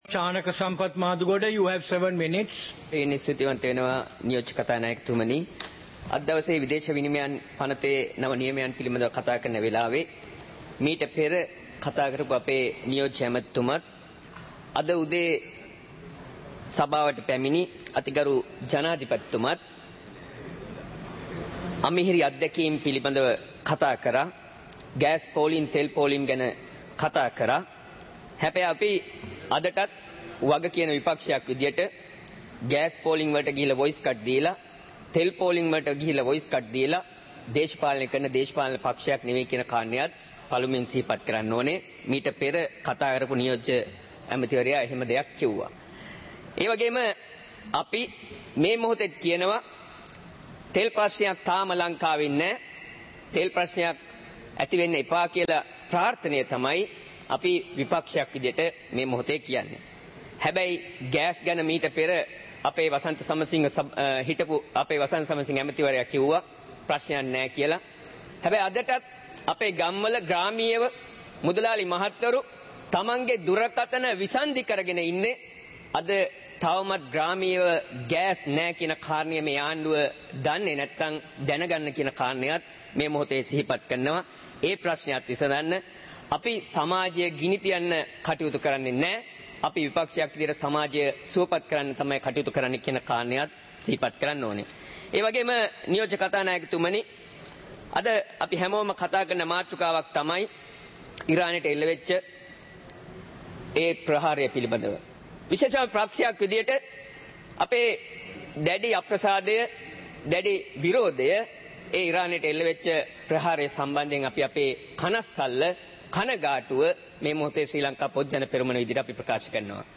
சபை நடவடிக்கைமுறை (2026-03-03)